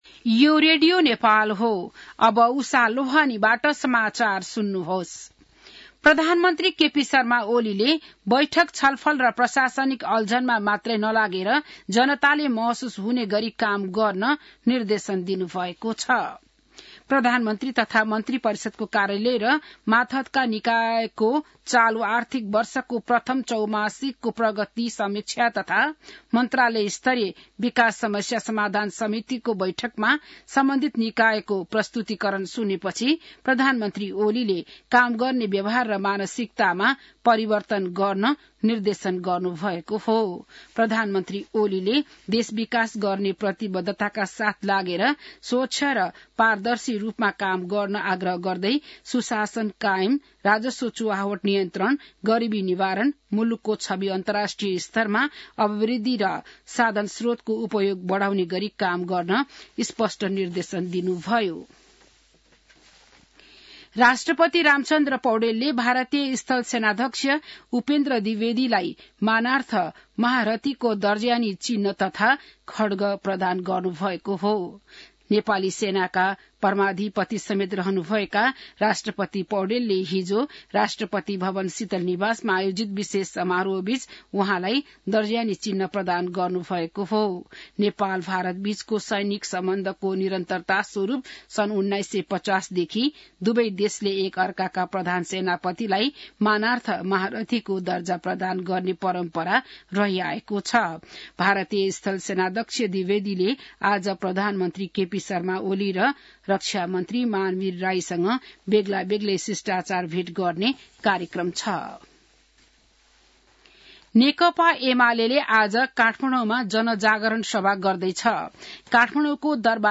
बिहान १० बजेको नेपाली समाचार : ८ मंसिर , २०८१